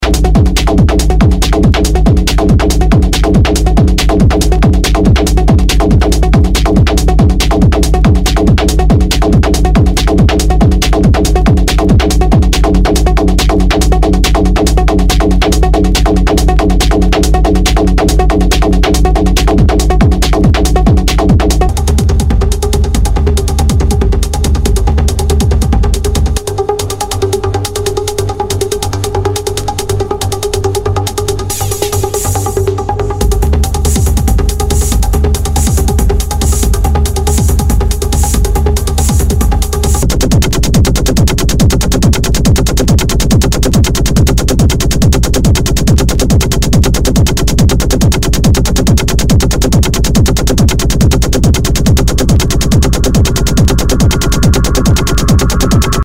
HOUSE/TECHNO/ELECTRO
ミニマル / テック・ハウス！